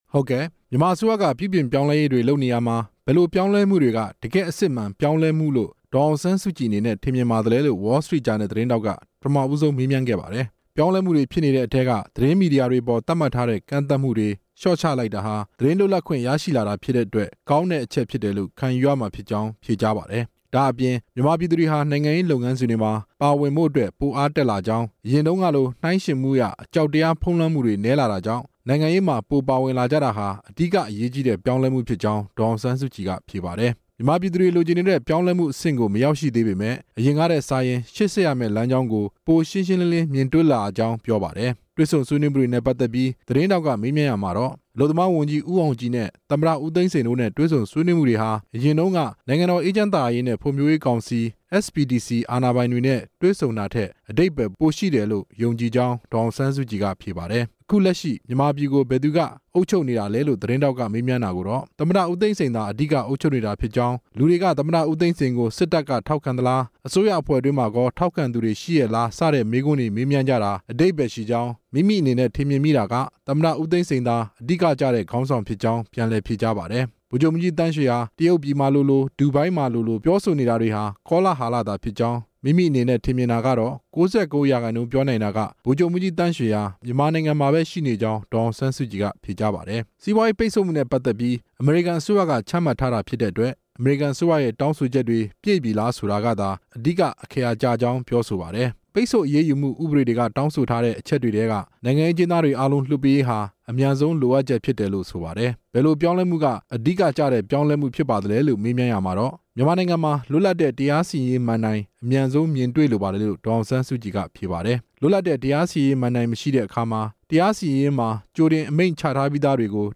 ဒေါ်အောင်ဆန်းစုကြည် ကို Wall Street Journal တွေ့ဆုံမေးမြန်း